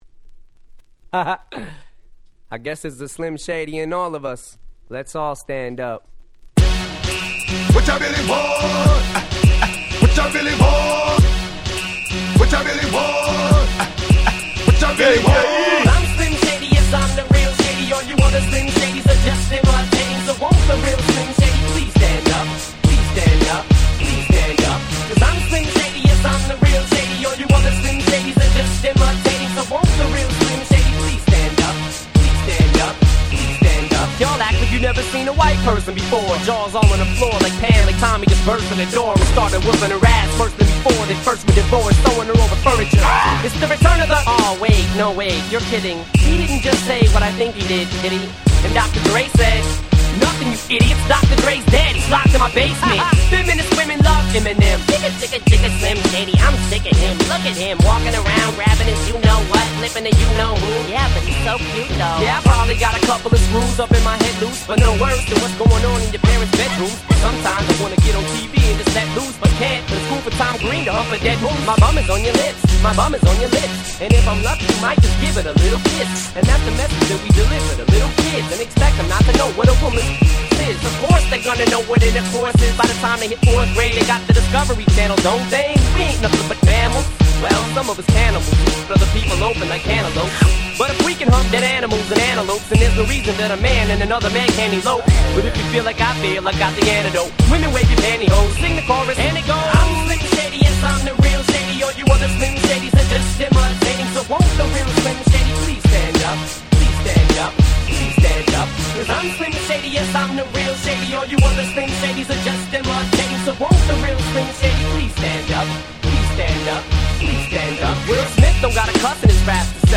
White Press Only Mash Ups !!